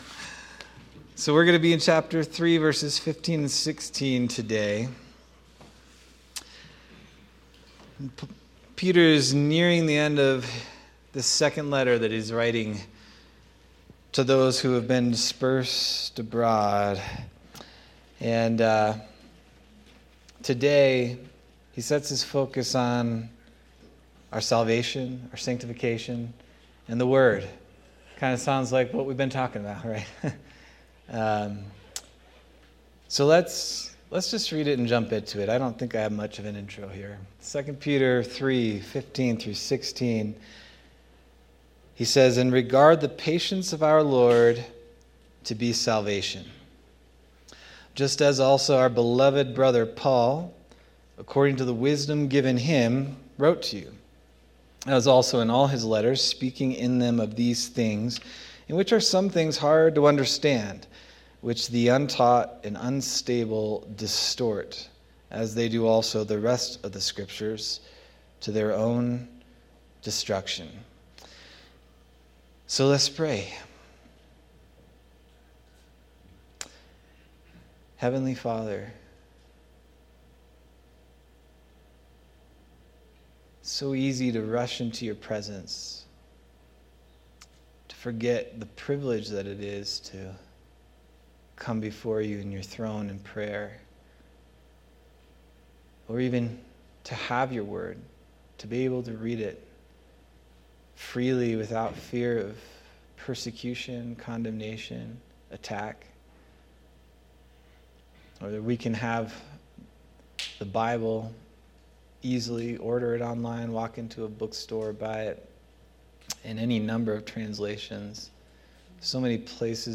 December 14th, 2025 Sermon